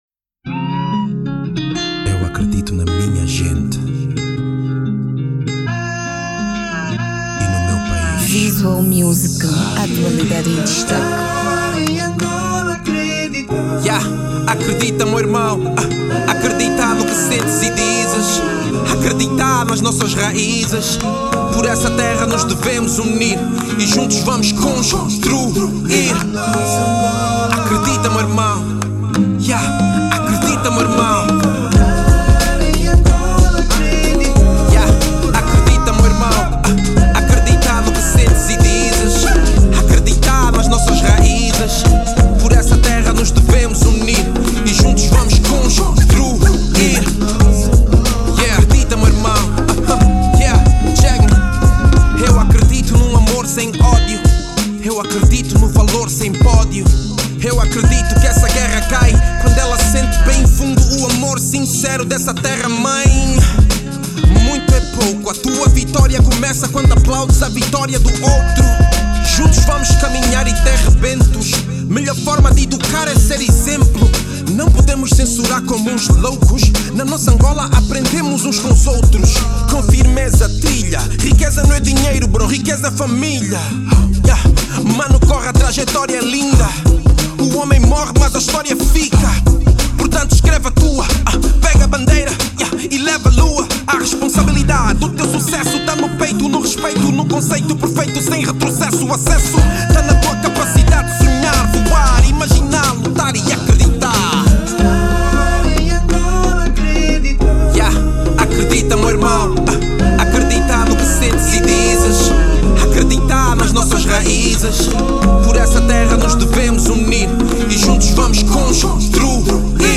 versão em Rap
RAP